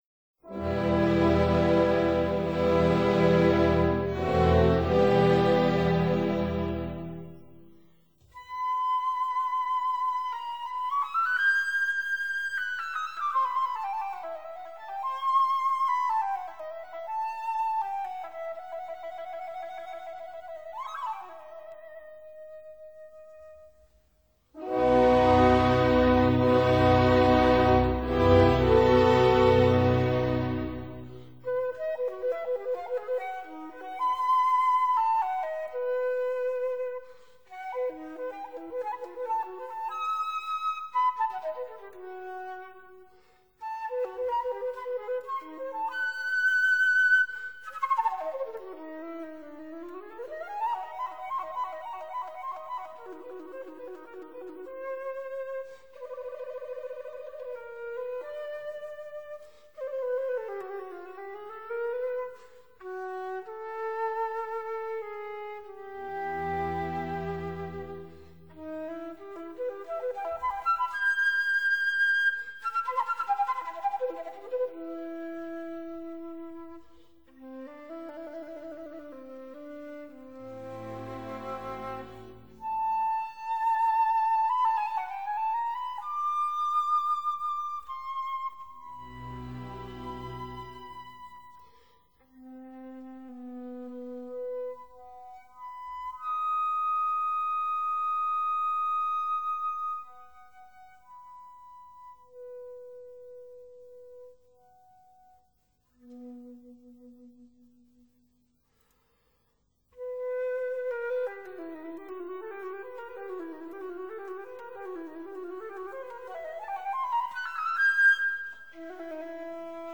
für Flöte und Orchester